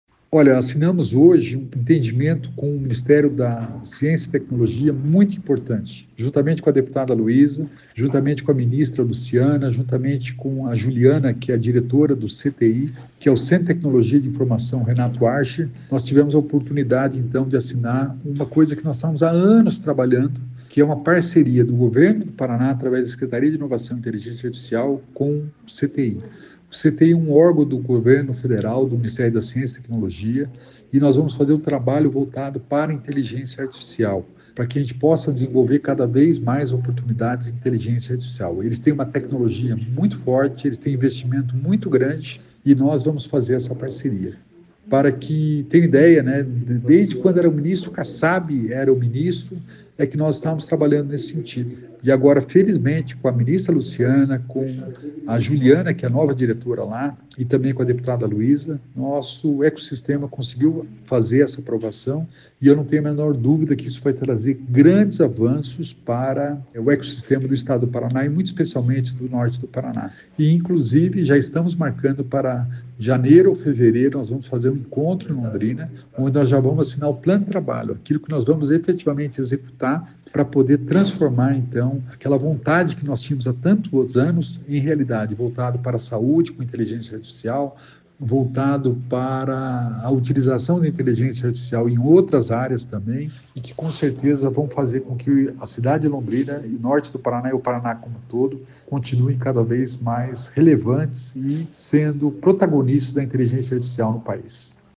Sonora do secretário da Inovação e Inteligência Artificial, Alex Canziani, sobre o acordo firmado com o Centro de Tecnologia da Informação Renato Archer